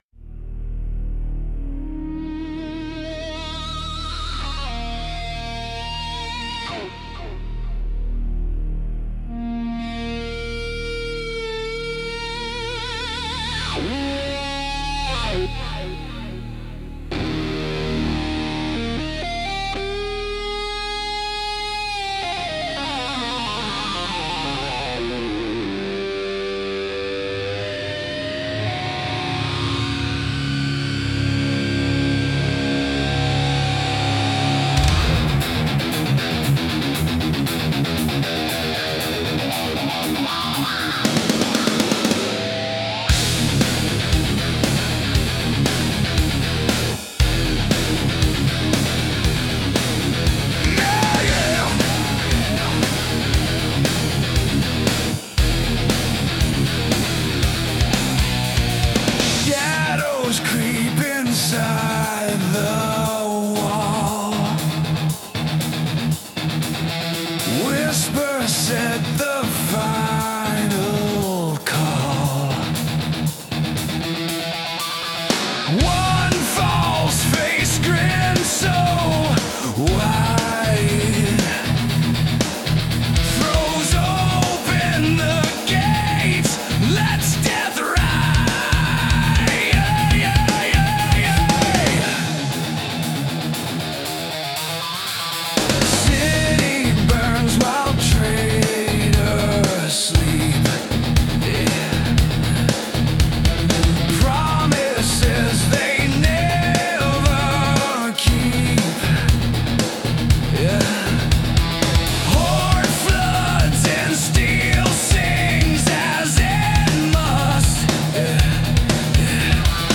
Danger: Rock and Roll Ahead.
I used Suno as the music creation A.I. Songs came out exactly like I wanted them to.